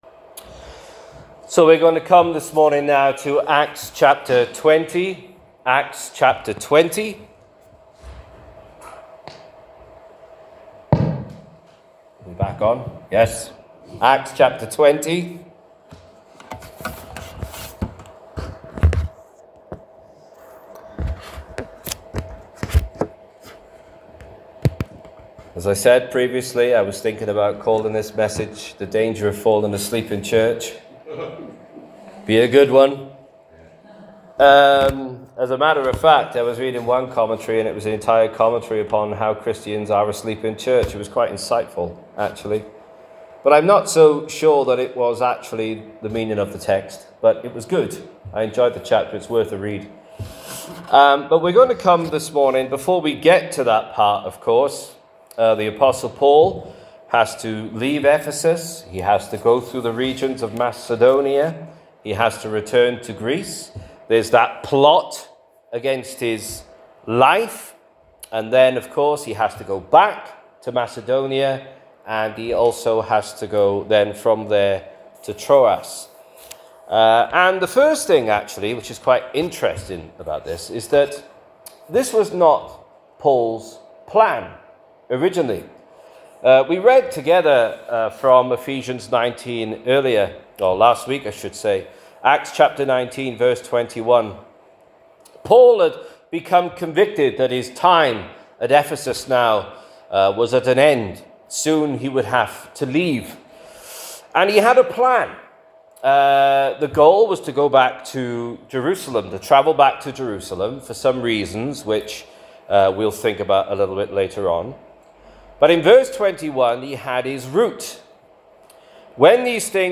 Sermon – Acts 20:1-12
This sermon was preached at Union Croft on the 1st February 2026.